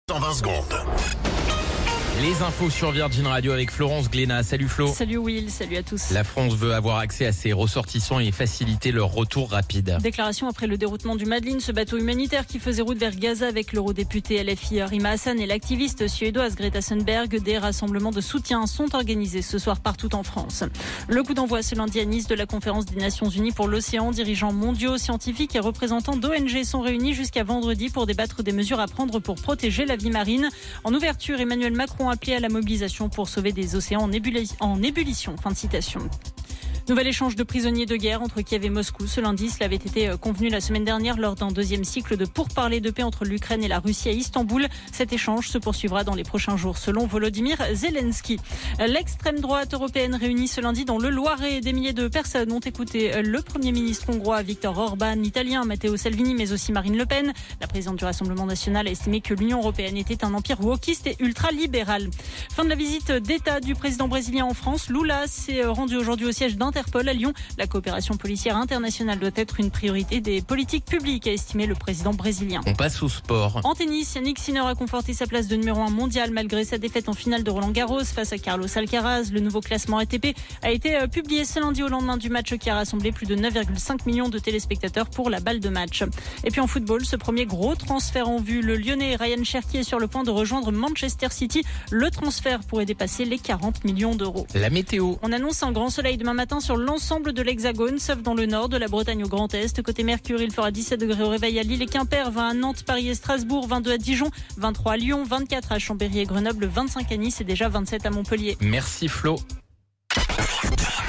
Flash Info National 09 Juin 2025 Du 09/06/2025 à 17h10 .